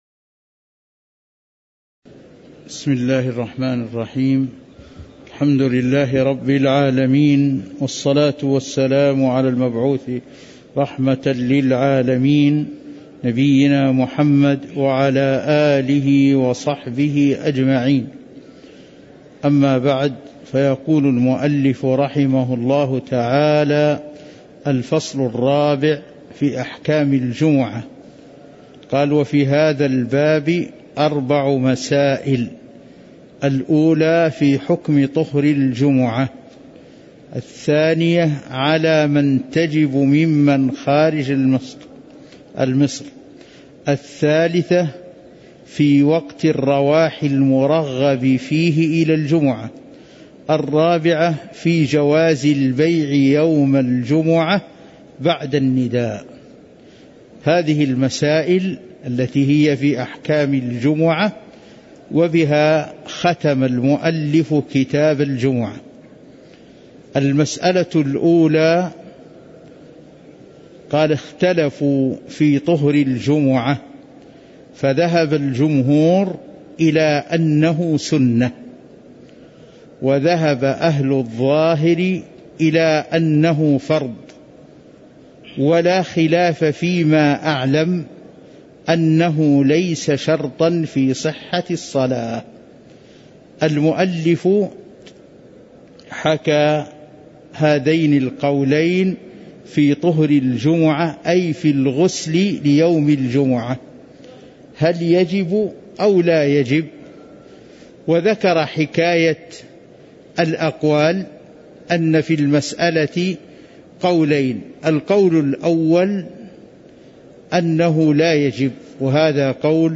تاريخ النشر ١٣ جمادى الآخرة ١٤٤٣ هـ المكان: المسجد النبوي الشيخ